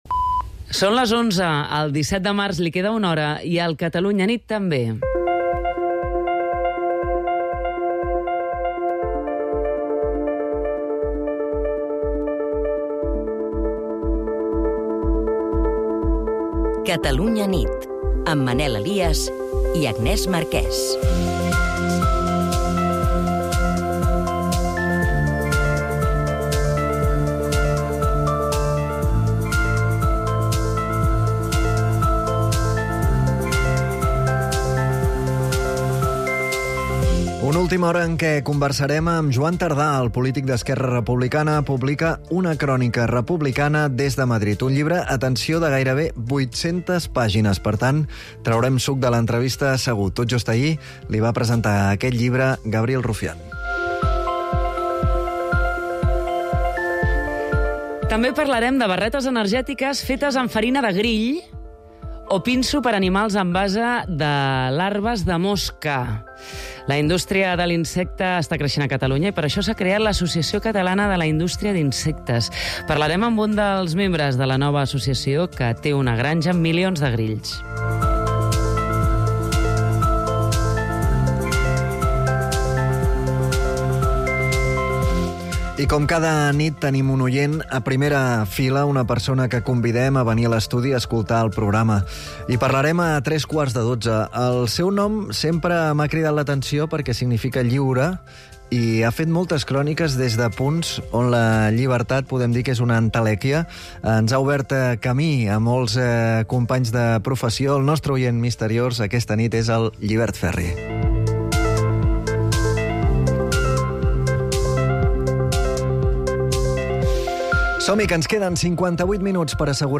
Informatius